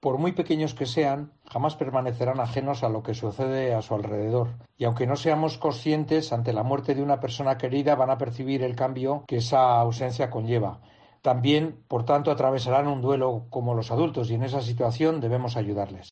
Jon Armentia, edil de Políticas Sociales de VItoria